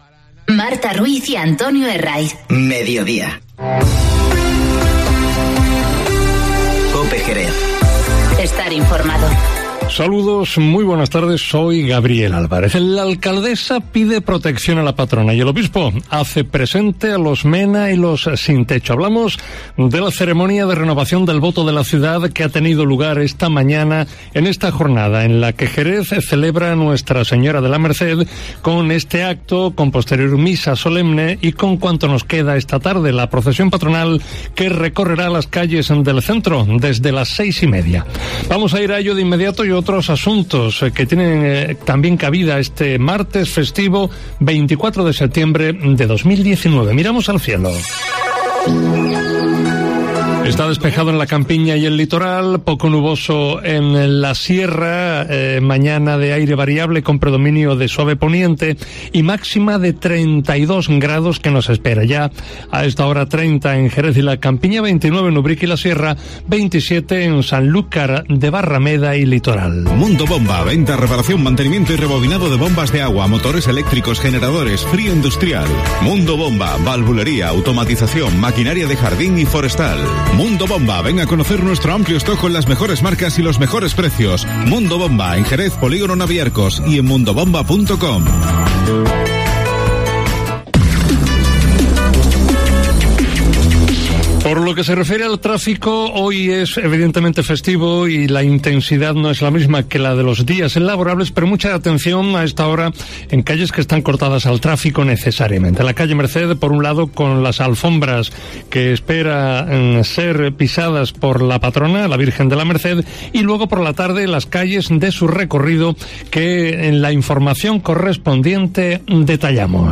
Informativo Mediodía COPE en Jerez 24-09-19